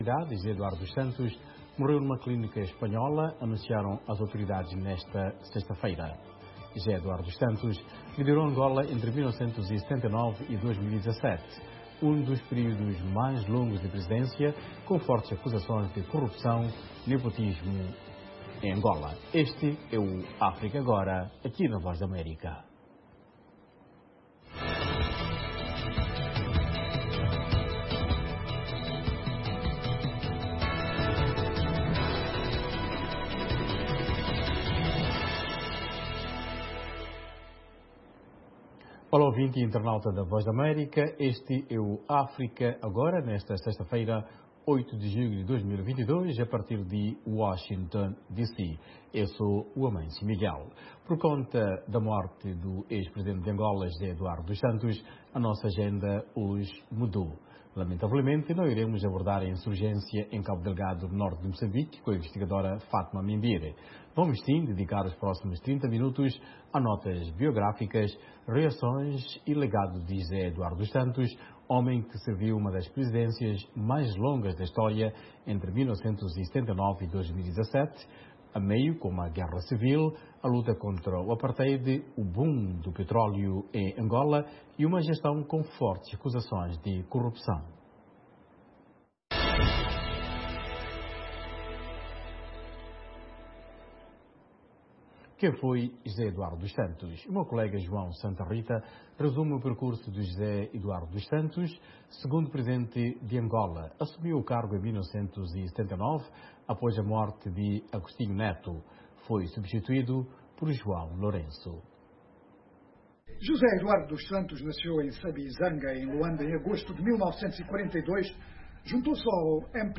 Um debate sobre temas actuais da África Lusófona